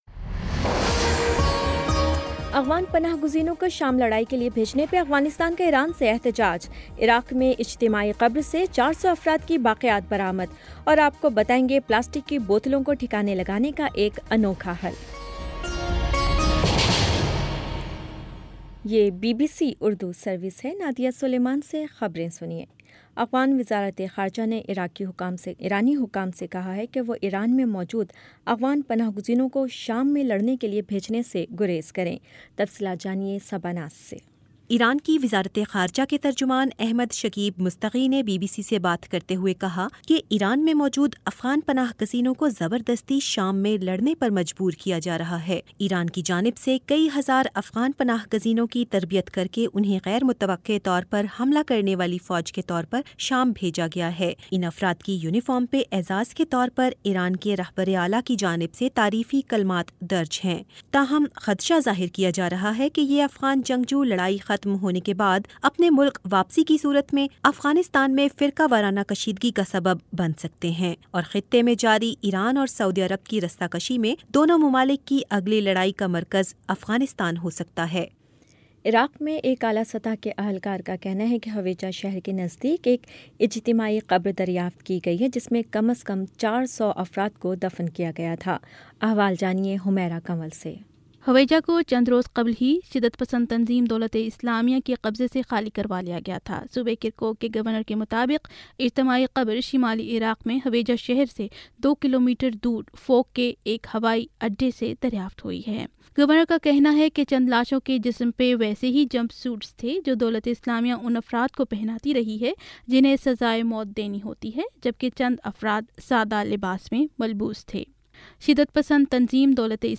نومبر 12 : شام چھ بجے کا نیوز بُلیٹن